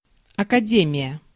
Ääntäminen
Ääntäminen : IPA: [əkɐˈdʲemʲɪjə] Haettu sana löytyi näillä lähdekielillä: venäjä Käännös Ääninäyte Substantiivit 1. academy RP US 2. college US UK Translitterointi: akademija.